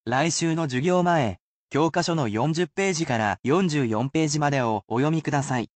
You can repeat after the word pronunciation, but the sentences are at regular speed in order to acclimate those learning to the pace.